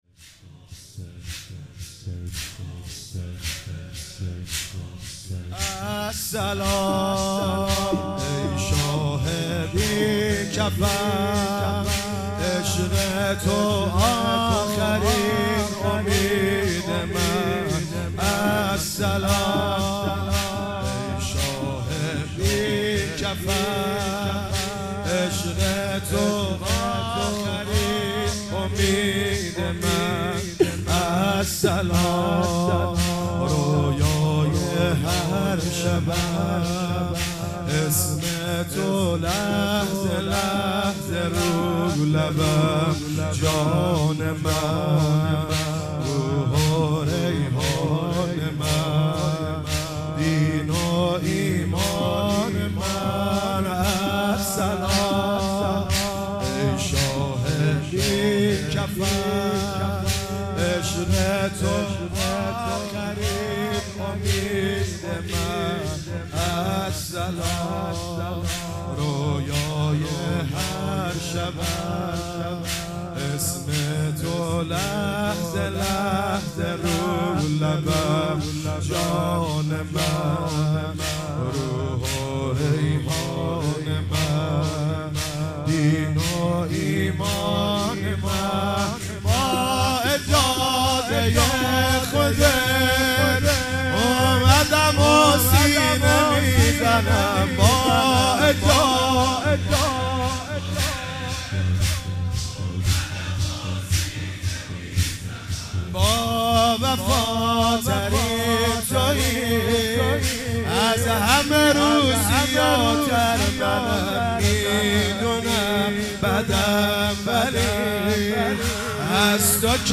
شب دوم محرم 97 - زمینه - السلام ای شاه بی کفن